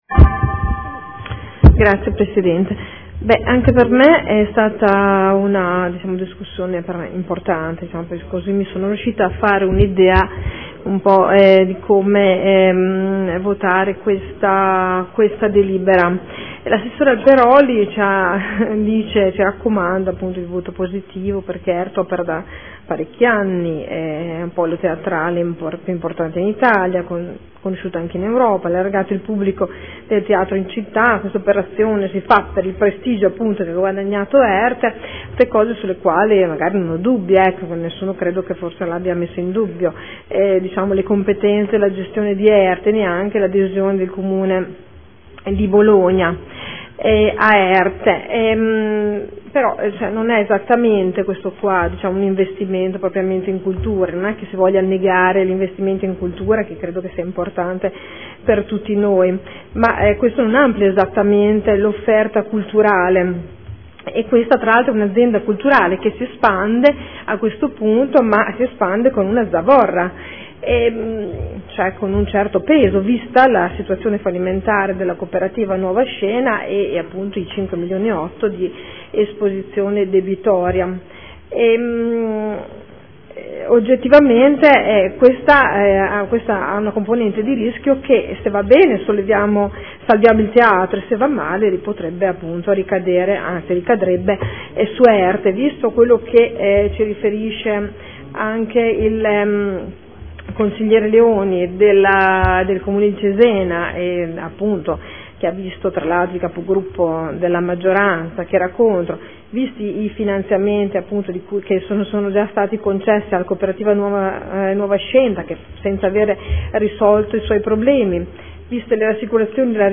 Sandra Poppi — Sito Audio Consiglio Comunale